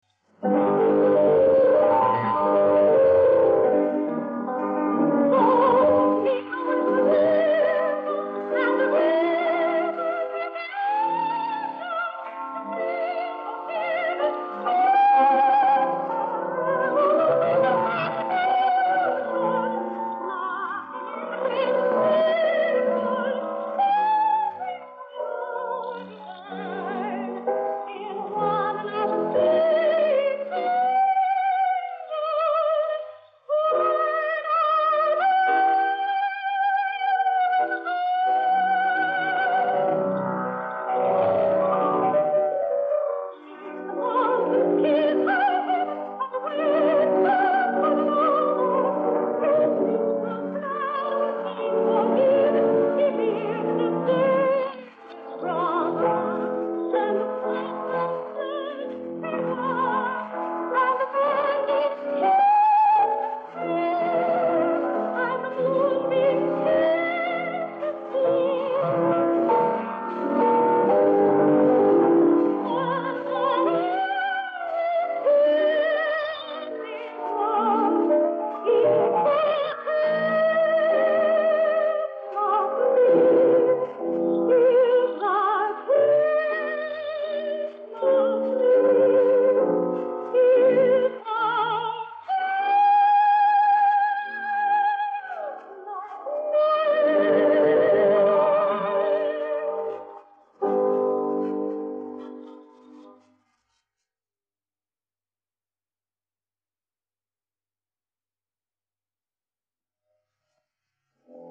who took her lovely soprano voice to Italy in June to see what could ‘be done with it.
Naturally the quality of the tape is poor
a lyric soprano